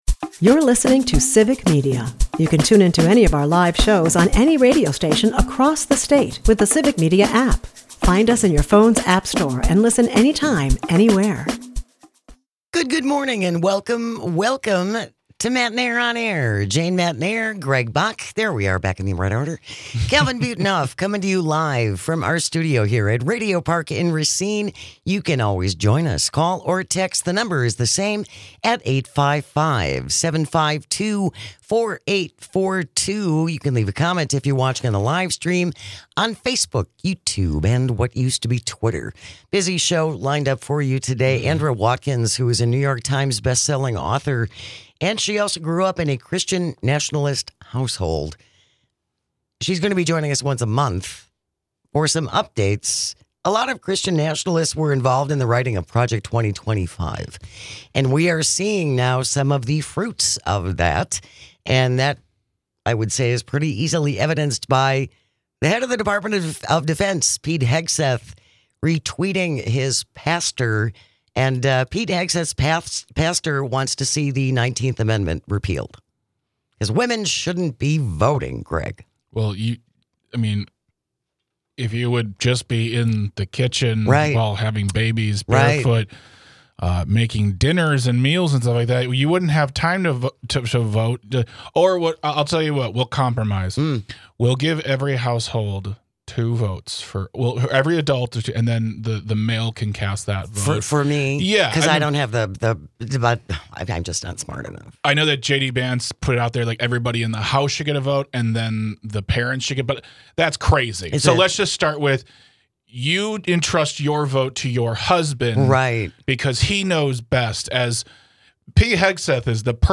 She joins us once a month to talk about how the White Christian Nationalist movement has "mainstreamed" their way into the halls of power in our country. As always, thank you for listening, texting and calling, we couldn't do this without you!